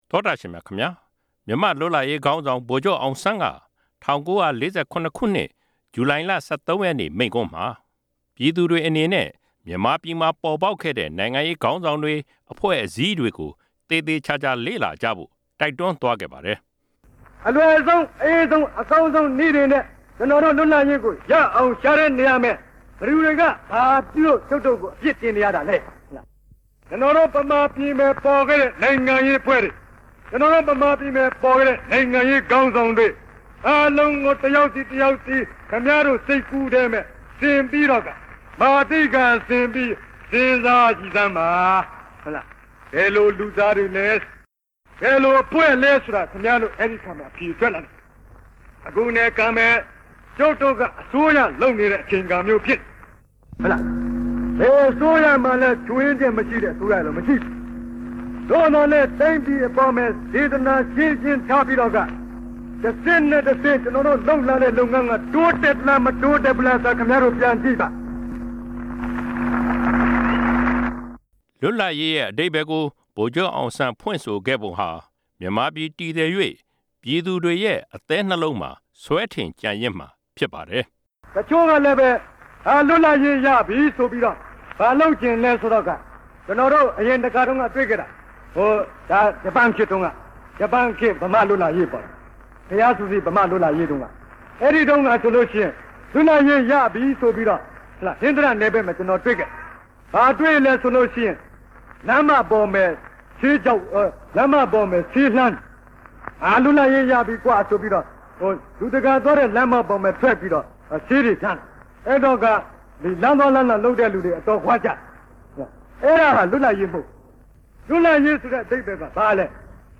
ဗိုလ်ချုပ်အောင်ဆန်း မိန့်ခွန်း